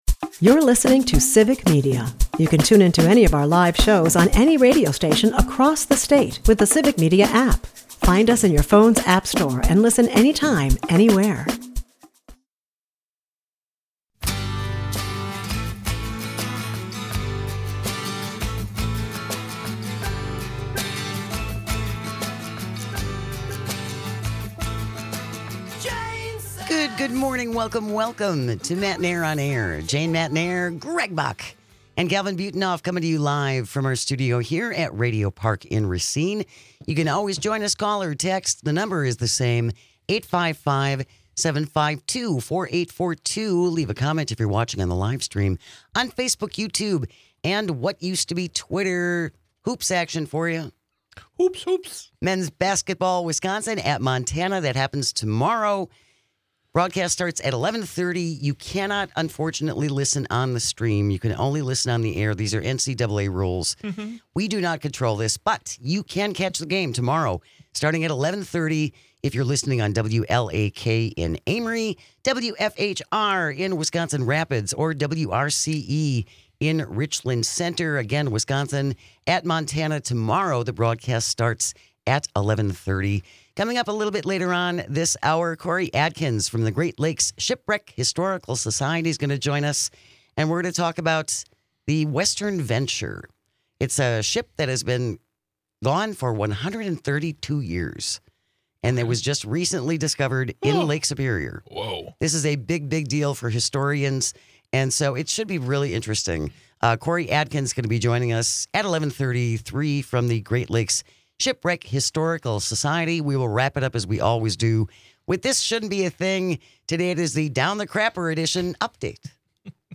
Matenaer On Air is a part of the Civic Media radio network and airs Monday through Friday from 10 am - noon across the state.